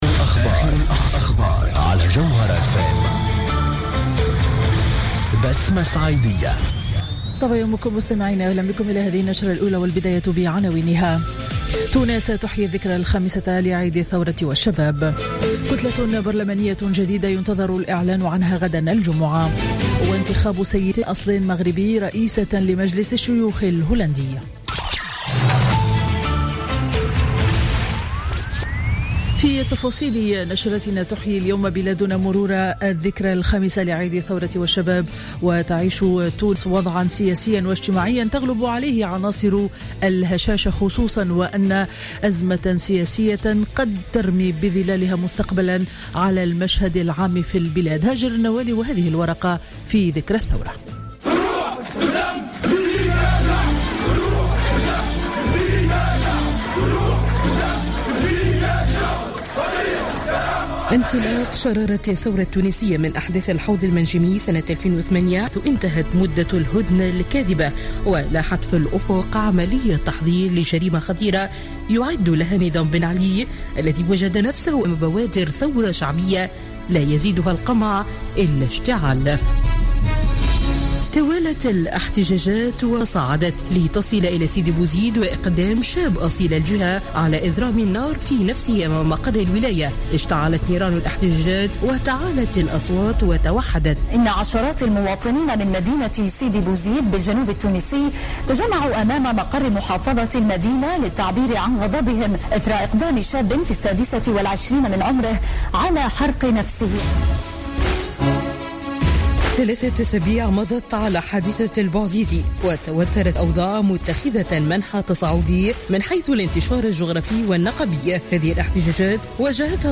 نشرة أخبار السابعة صباحا ليوم الخميس 14 جانفي 2016